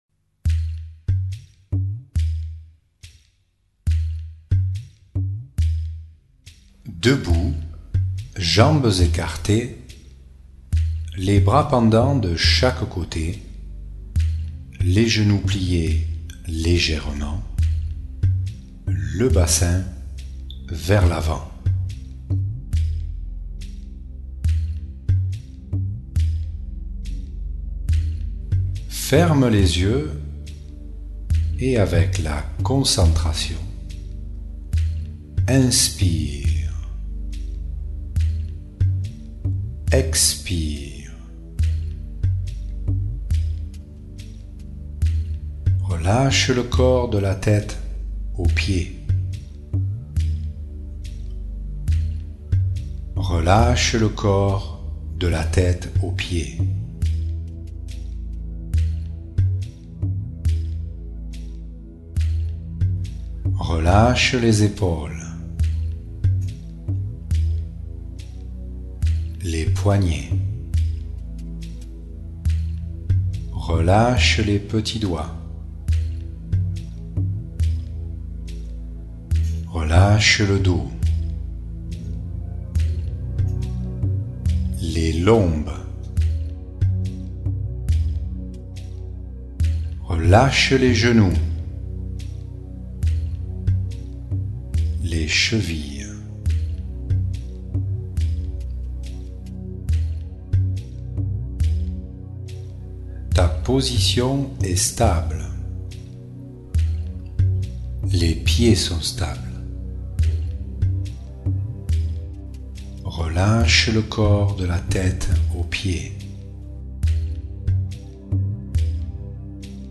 MeditationArbre02.mp3